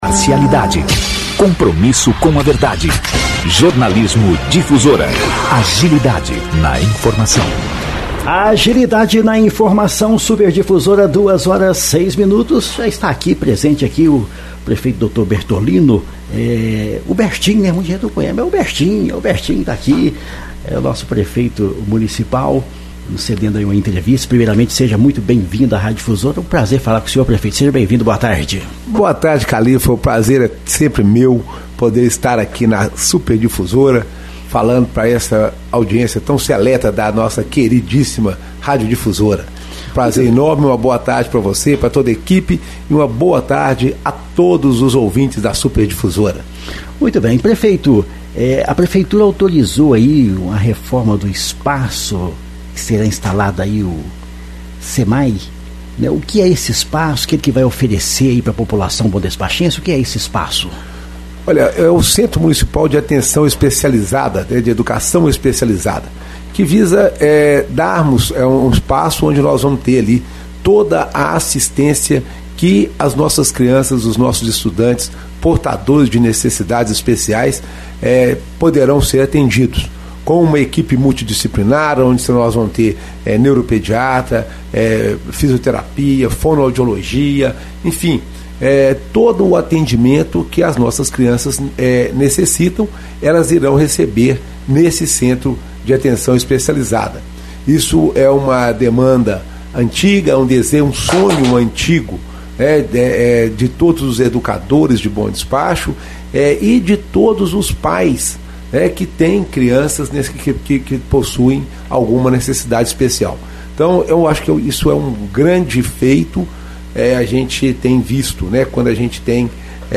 Ontem (dia 9) o prefeito Doutor Bertolino deu entrevista à Rádio Difusora. Na oportunidade ele falou sobre as obras de reforma e adequação para instalação do Centro Municipal de Atendimento Especializado de Bom Despacho (Cemae) e as comemorações do aniversário de 110 anos de Bom Despacho e muito mais.